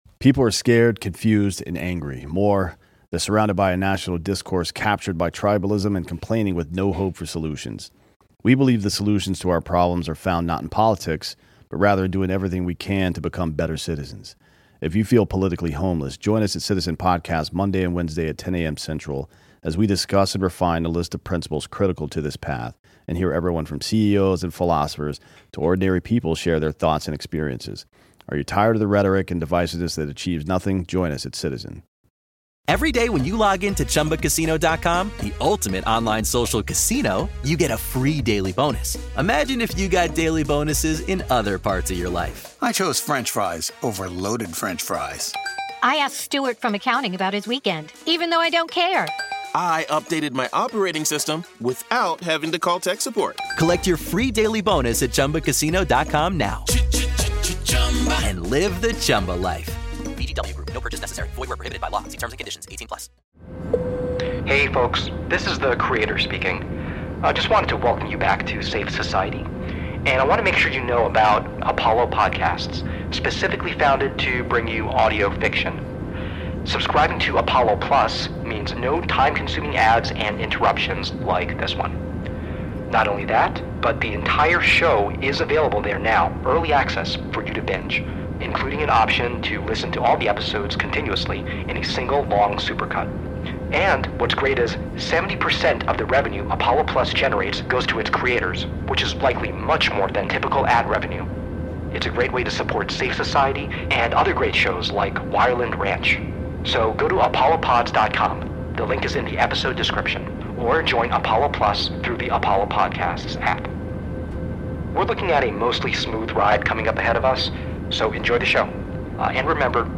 B and a band of Rebels force M to go cold turkey from his neuroses. M escapes from Sofia with his kids. CONTENT WARNINGS Moderate language, moments of unsettling, intense, and sudden loud sound design and unsettling voices, violence, allusions to neuro.